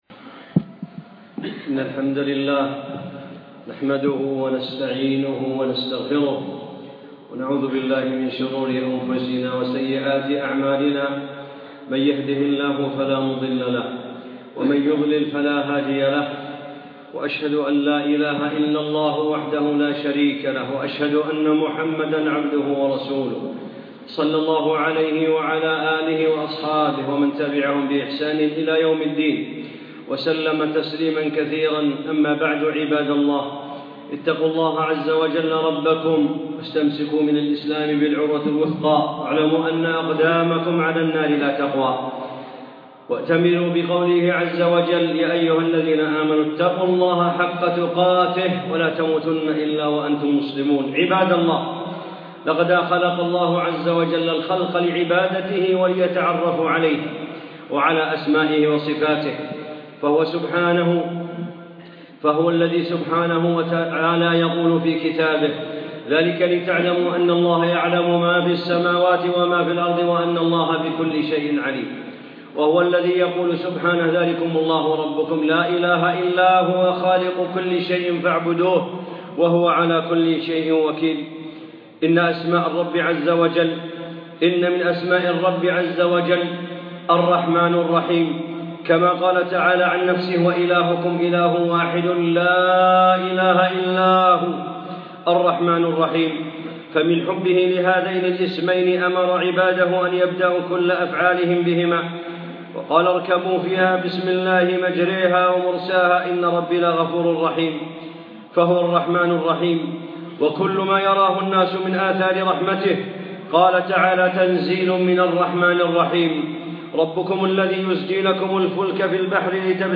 خطبة - حق الله على العباد